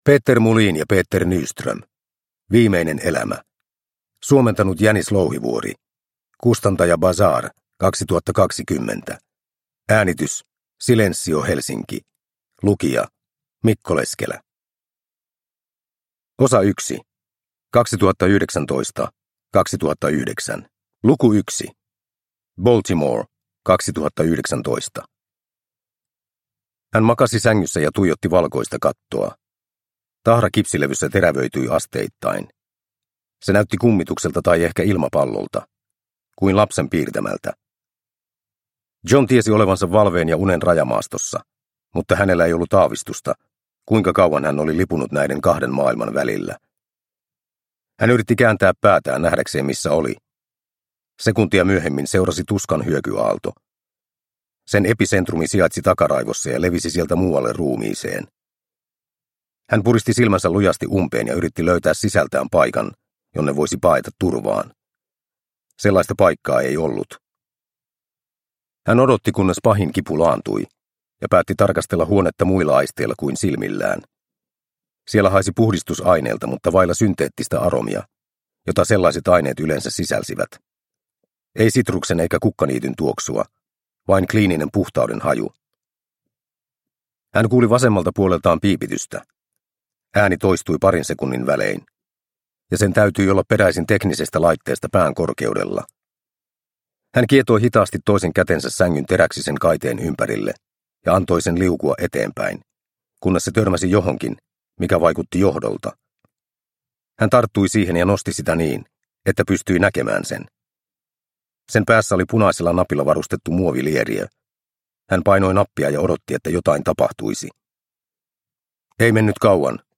Viimeinen elämä – Ljudbok – Laddas ner